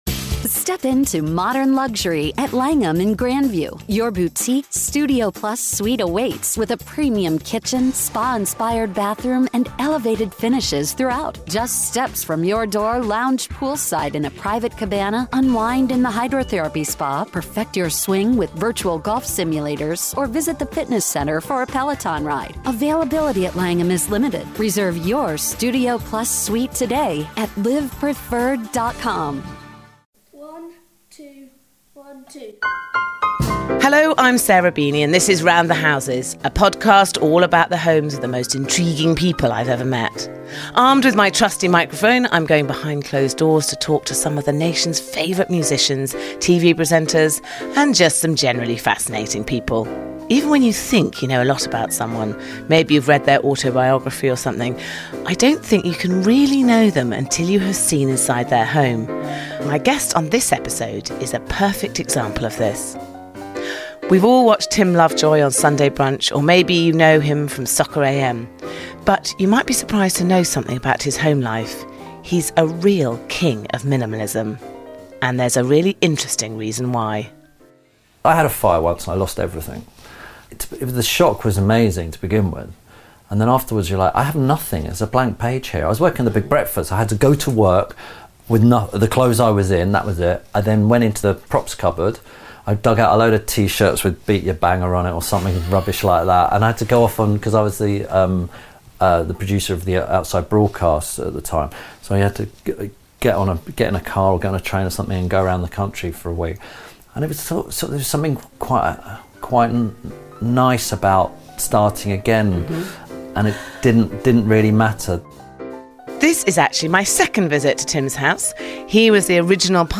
This week Sarah Beeny visits the home of presenter - and fellow podcaster - Tim Lovejoy.